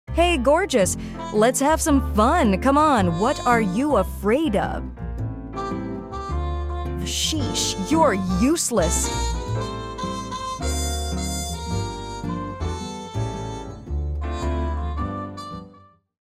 You're Useless! - Lion courtship